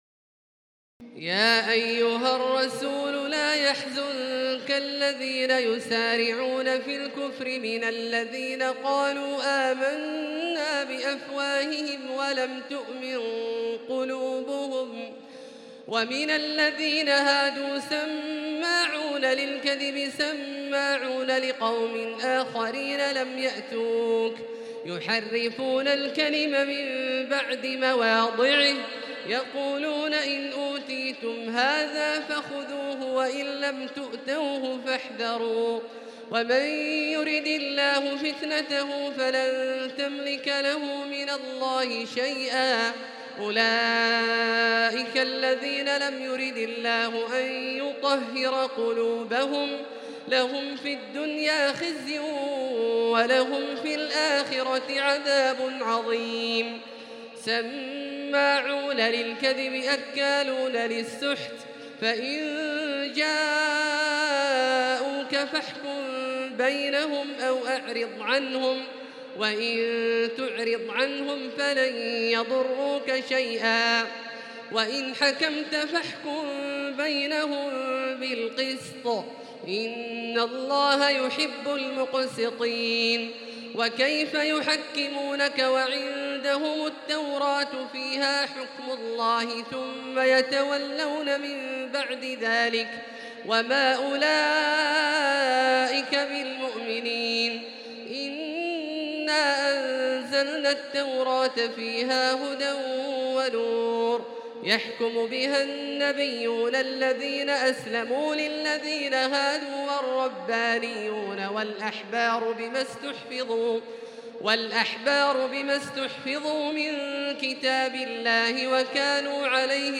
تراويح الليلة السادسة رمضان 1438هـ من سورة المائدة (41-108) Taraweeh 6 st night Ramadan 1438H from Surah AlMa'idah > تراويح الحرم المكي عام 1438 🕋 > التراويح - تلاوات الحرمين